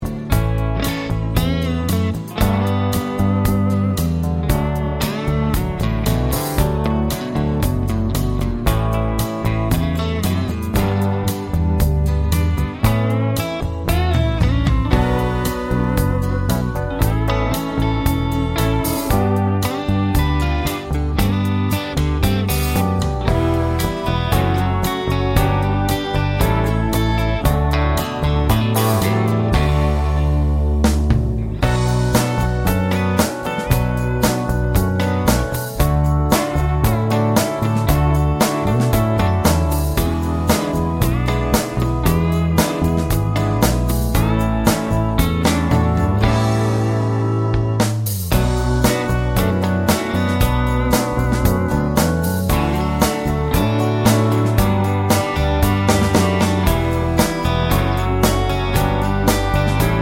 no Backing Vocals or Crowd SFX Country (Male) 3:51 Buy £1.50